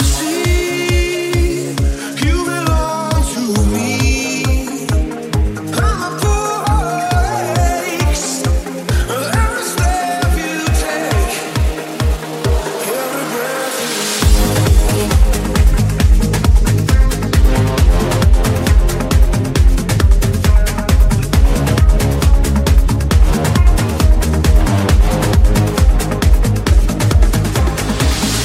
Genere: afrobeat,afrohouse,deep,remix,hit